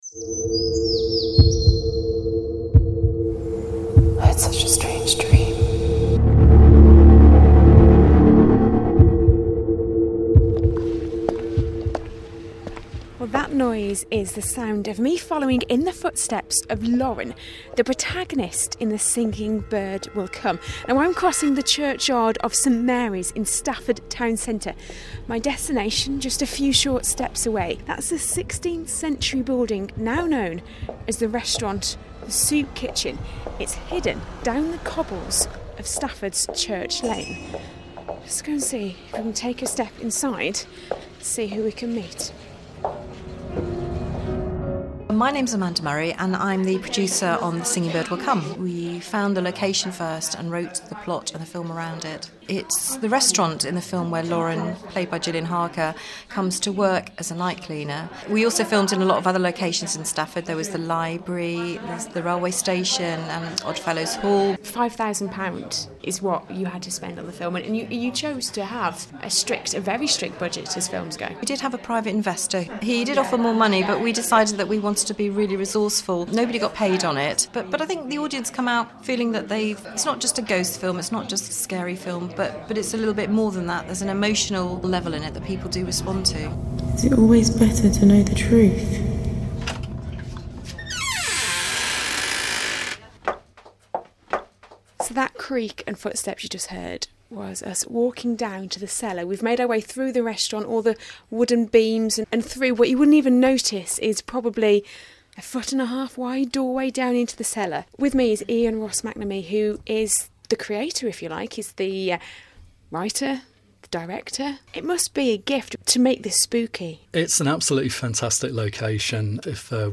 (Broadcast on BBC Radio Stoke, April 2015)